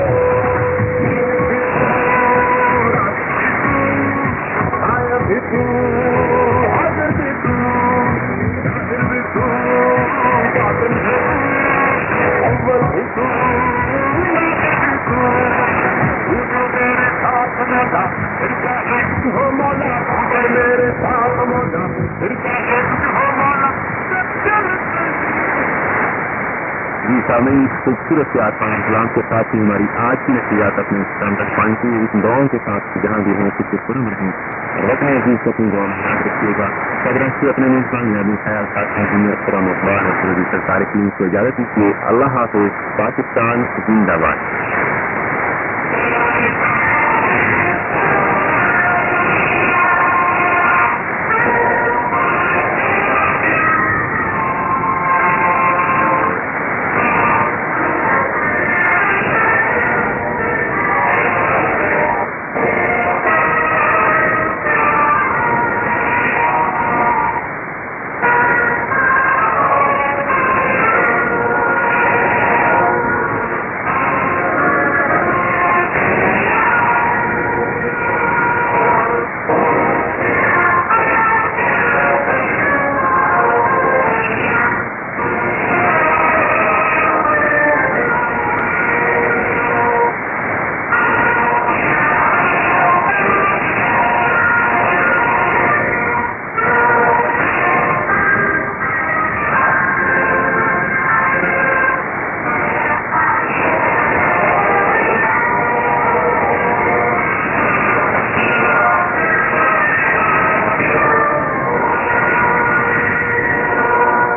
・このＨＰに載ってい音声(ＩＳとＩＤ等)は、当家(POST No. 488-xxxx)愛知県尾張旭市で受信した物です。